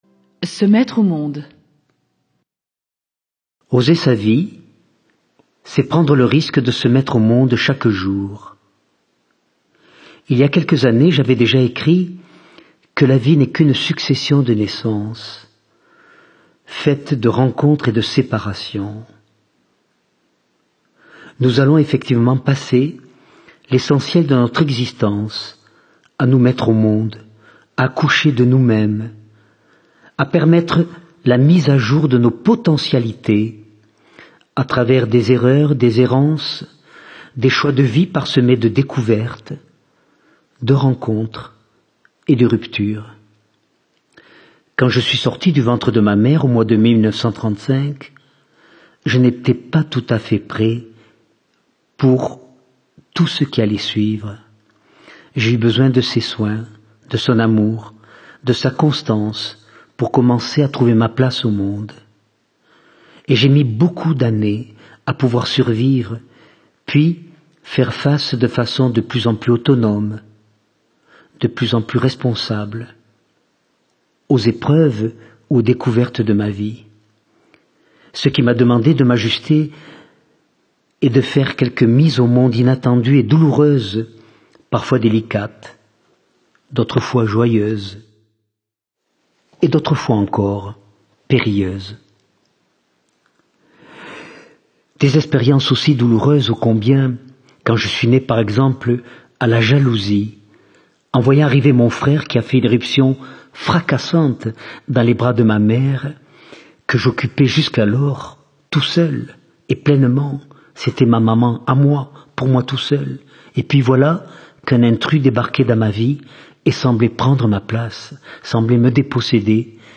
Lire un extrait Jacques Salomé Vivre au présent Coffragants Date de publication : 2010 En phase avec les brûlantes interrogations actuelles, Jacques Salomé répond, avec son expérience de la vie, aux attentes et questions de ses lectrices et lecteurs sur tous les problèmes existentiels qu’ils se posent aujourd’hui. Un livre-audio de réflexions utiles pour notre évolution intérieure et pour un mieux être.